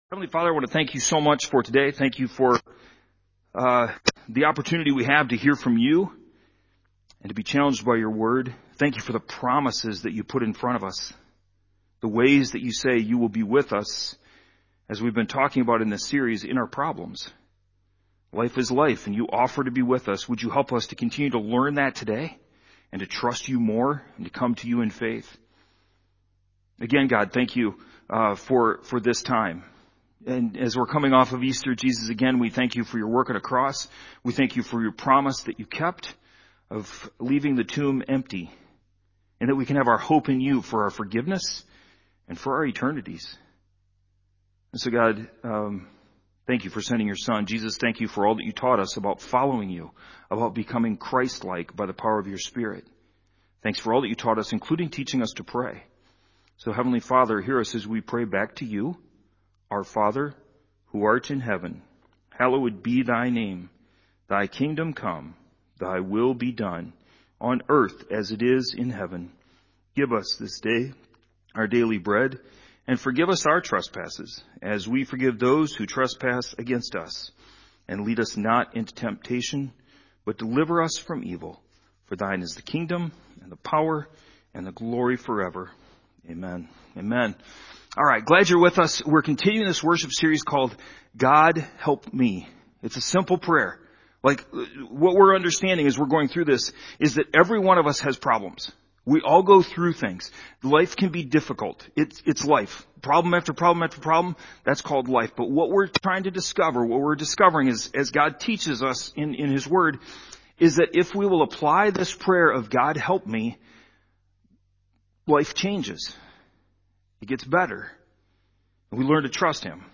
A message from the series "GOD HELP ME!."